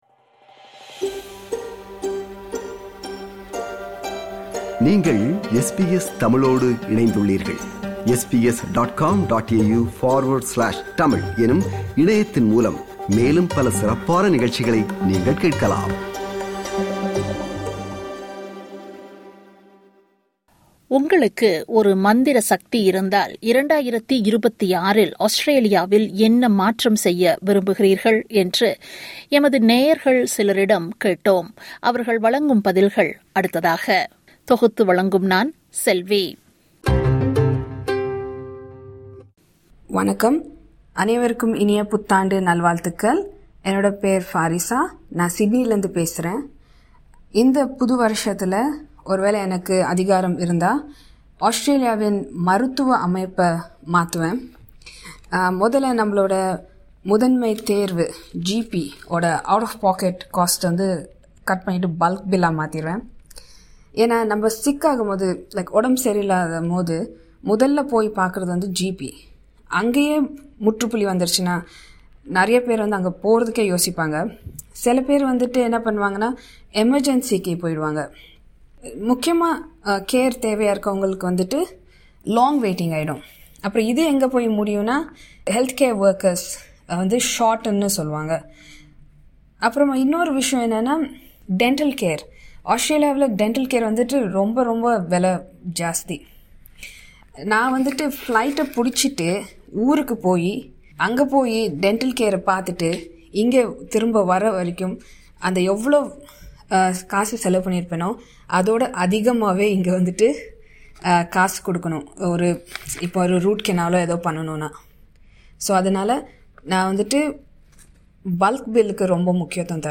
உங்களுக்கு ஒரு மந்திர சக்தி கிடைத்தால் 2026இல் ஆஸ்திரேலியாவில் என்ன மாற்றம் செய்ய விரும்புகிறீர்கள் என்று எமது நேயர்கள் சிலரிடம் கேட்டோம்.